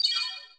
camera_shutter_crystal.wav